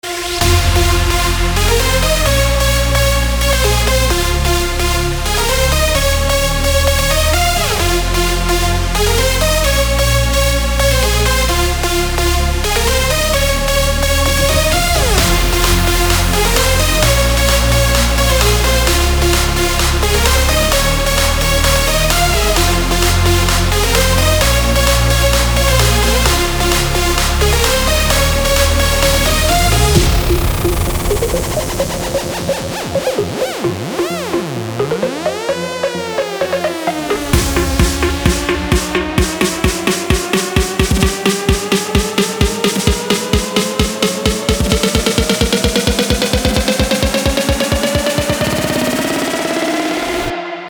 • Качество: 320, Stereo
dance
Electronic
без слов
club